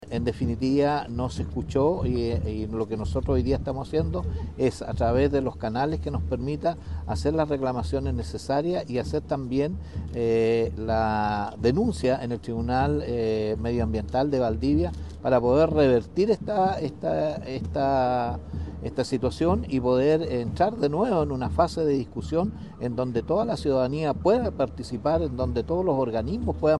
Así lo señaló Ricardo Fuentes, alcalde de Florida y presidente de la Asociación de Municipalidades de la Región del Bío Bío y Valle Nonguén.